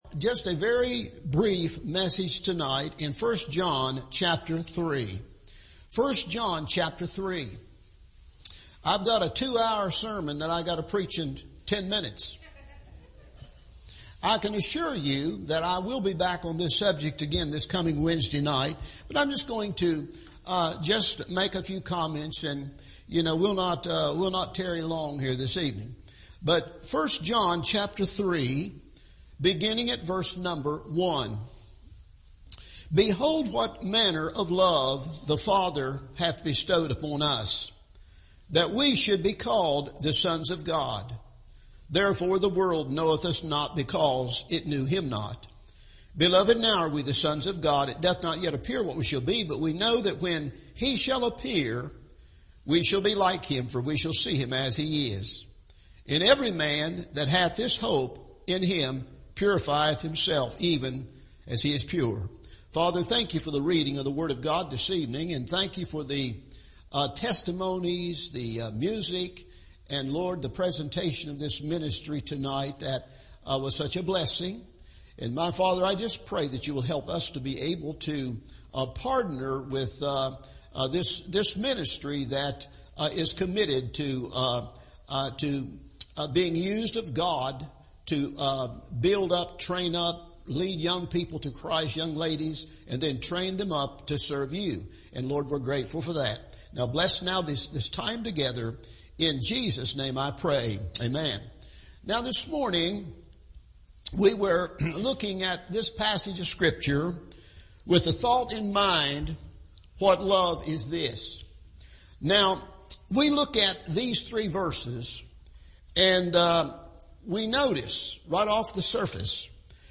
What Love is This? – Part Two – Evening Service